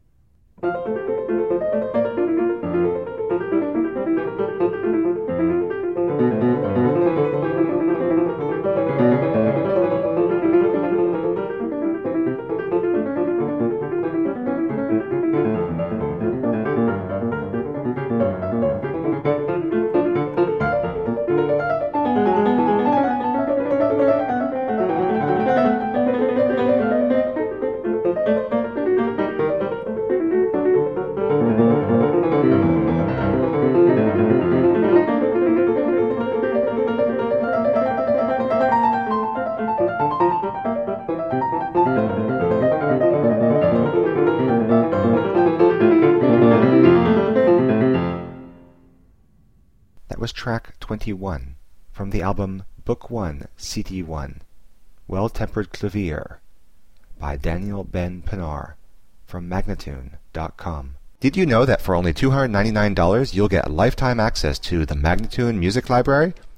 Virtuoso pianist
Classical, Baroque, Instrumental Classical, Classical Piano